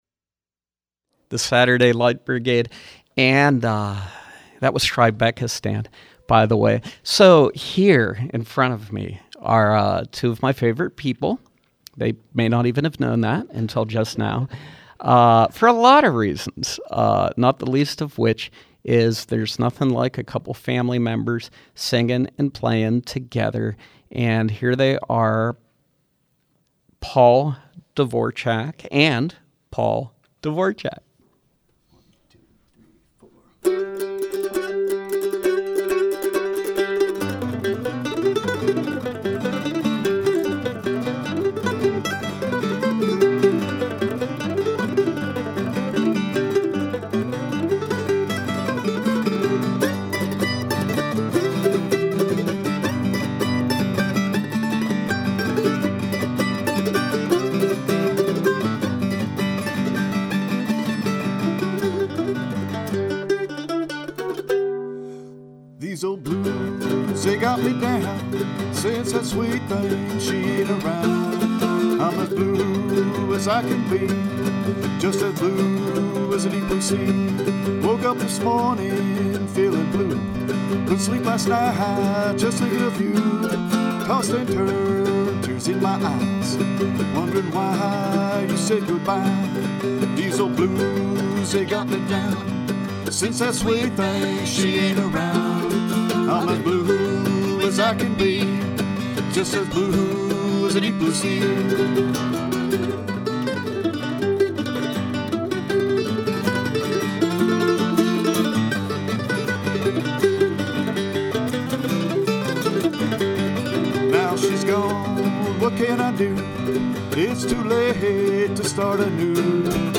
bluegrass and folk
mandolin and guitar